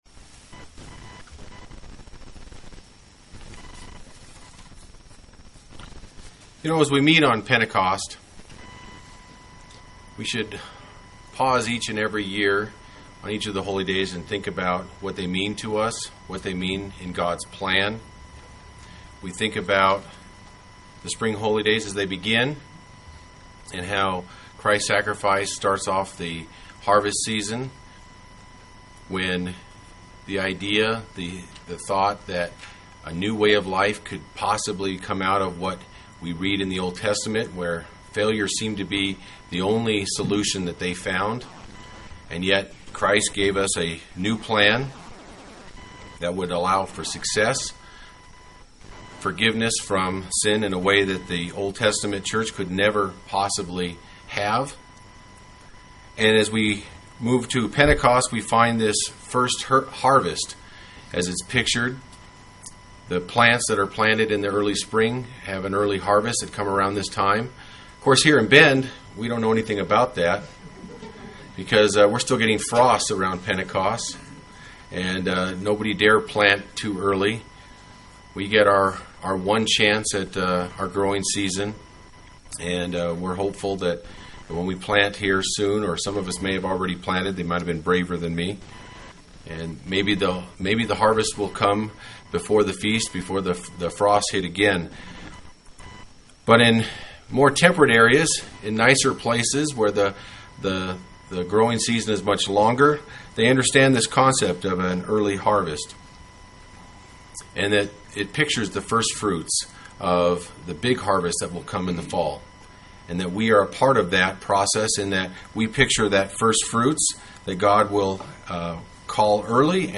The title of the Sermon today is Pray for the RAIN of God’s Spirit and the Reign of His Kingdom Today we will be talking of Rain and Reign because they are linked.